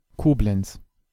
Koblenz (UK: /kˈblɛnts/ koh-BLENTS, US: /ˈkblɛnts/ KOH-blents, German: [ˈkoːblɛnts]
De-Koblenz.ogg.mp3